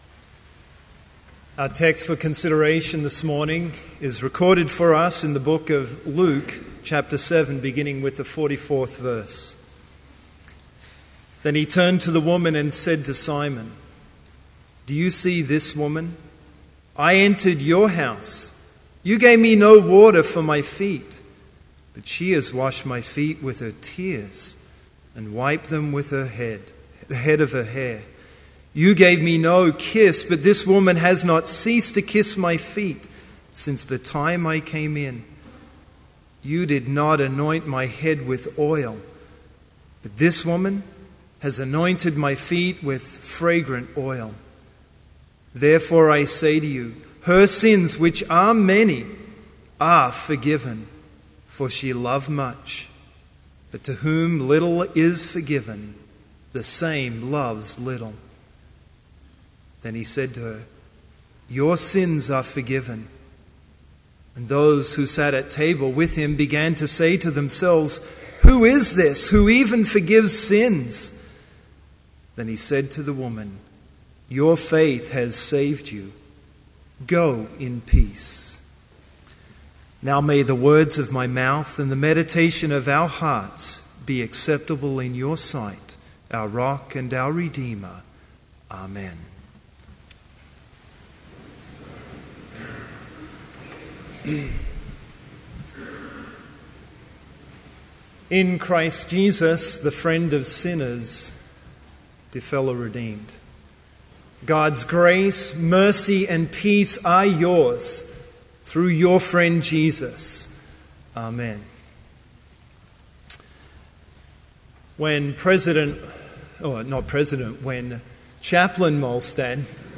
Complete service audio for Chapel - September 11, 2012